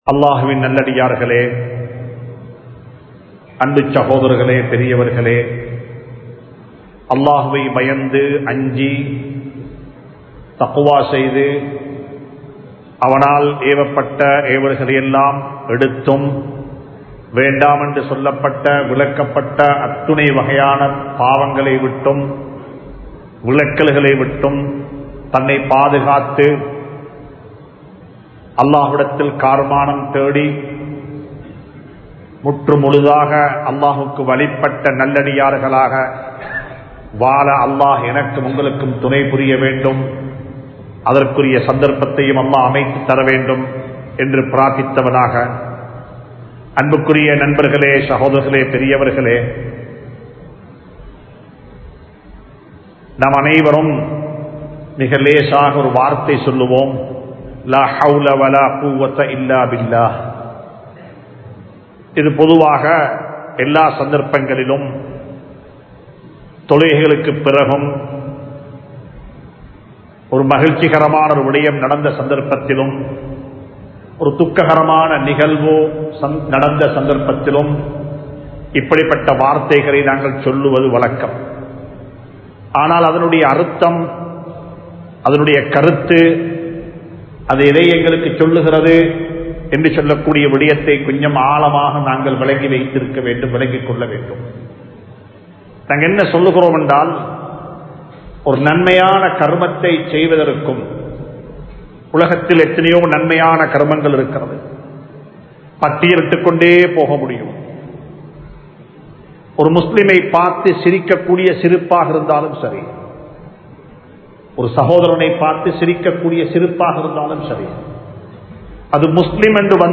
Padaiththavanin Sakthi (படைத்தவனின் சக்தி) | Audio Bayans | All Ceylon Muslim Youth Community | Addalaichenai
Majma Ul Khairah Jumua Masjith (Nimal Road)